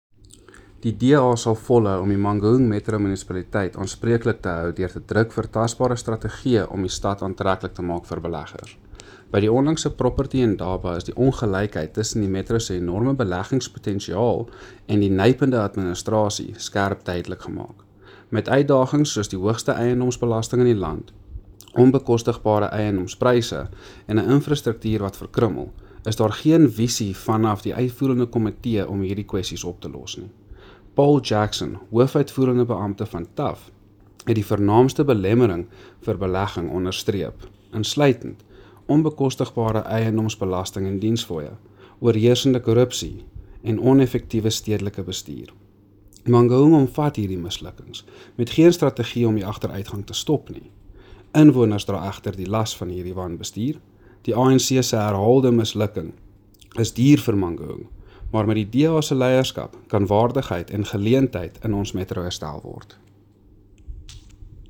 Afrikaans soundbites by Cllr Andre Snyman and